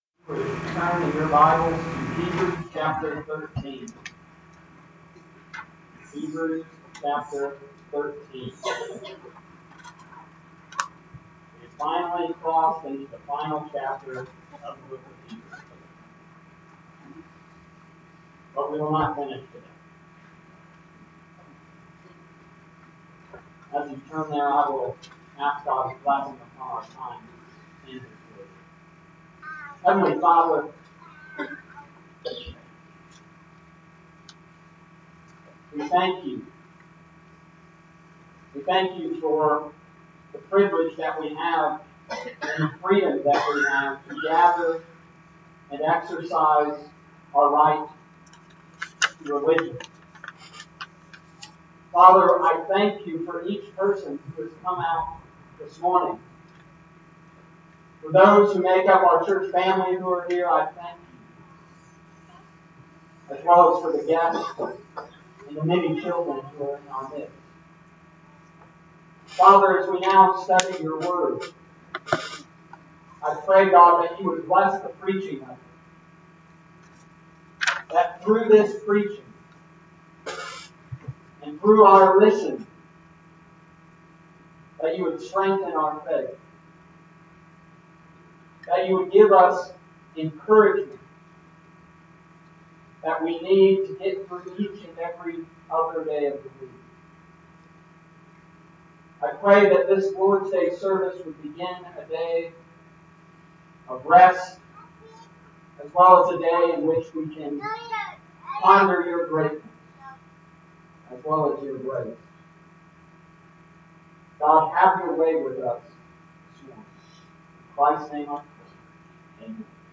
Summary of Sermon: This week, we learned that we need to worship God.